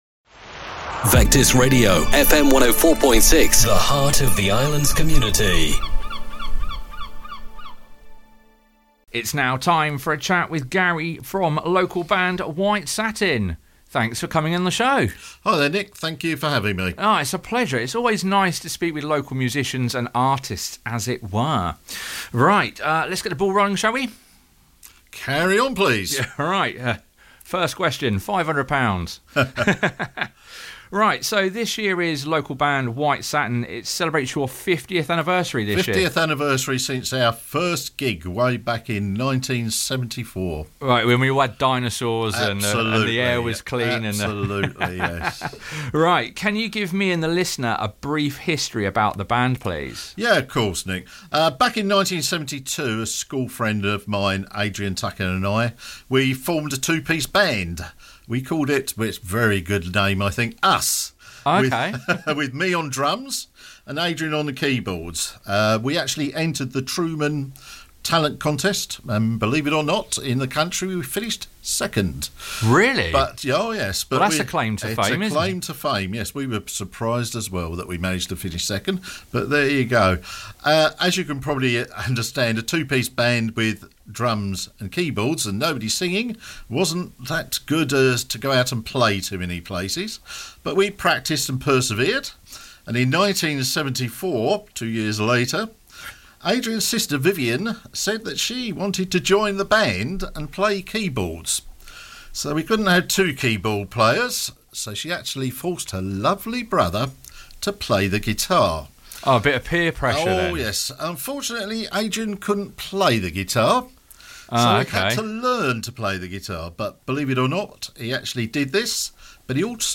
If you missed the original broadcast here is the podcasted version of the chat